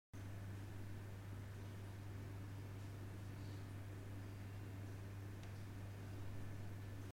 Too Much Static While Recording
Let me write down the equipment I am using for recording :- Behringer B-1 Behringer Phantom Power Supply Behringer UControl UCA202 BIG PMX-4D MIXER Windows While I record, I get alot of static. I mean, there is alot of noise (not distortion) but yes definitely there is something.
Please note, I am using Behringer B1 Mic with a Phantom Power supply.